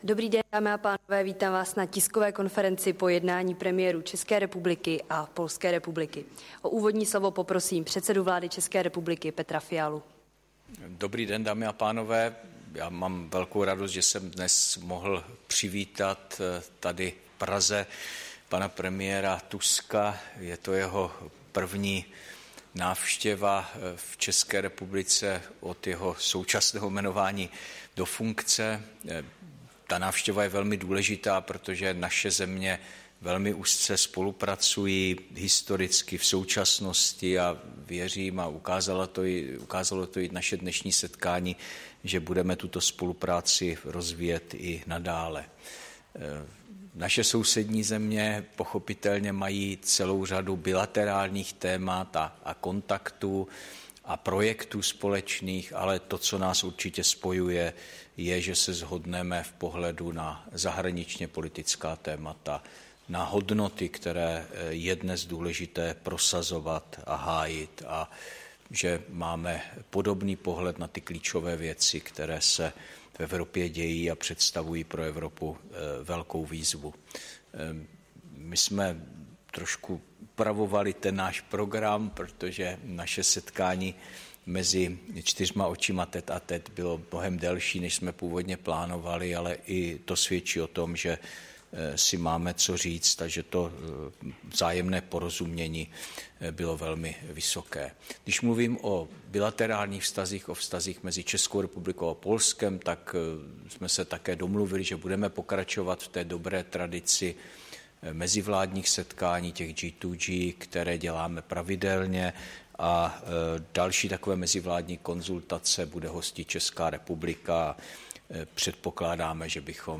Tisková konference českého premiéra Petra Fialy s polským premiérem Donaldem Tuskem, 27. února 2024